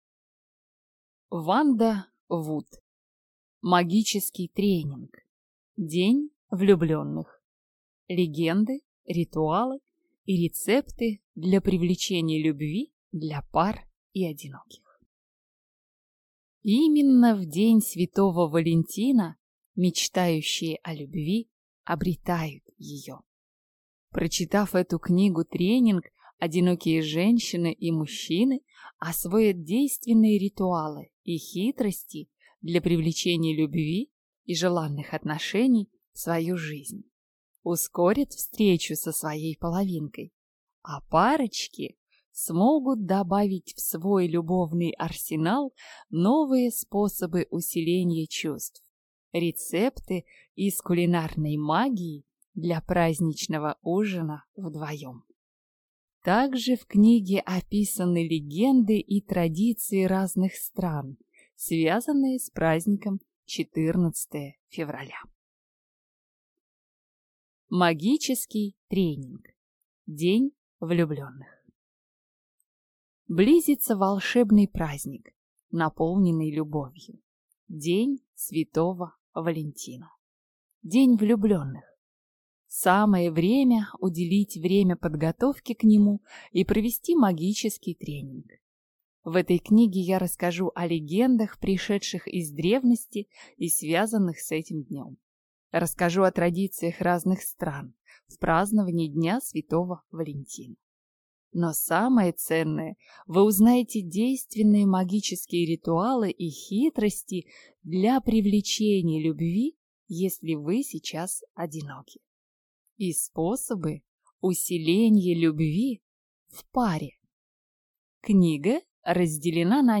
Аудиокнига Магический тренинг. День влюбленных. Легенды, ритуалы и рецепты для привлечения любви для пар и одиноких | Библиотека аудиокниг